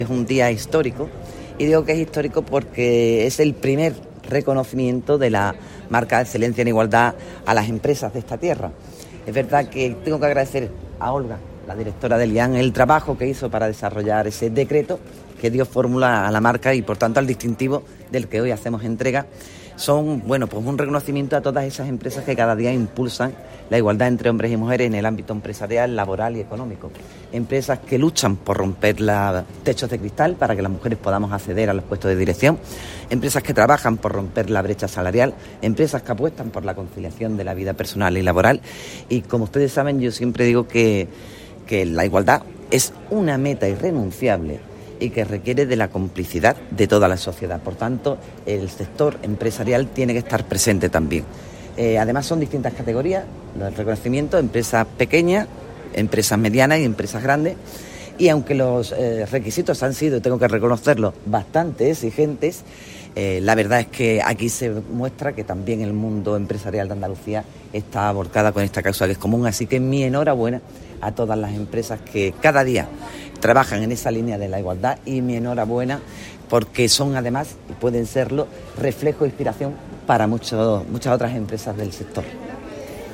Entrega-reconocimientos-Marca-Andaluza-Excelencia-en-Igualdad.mp3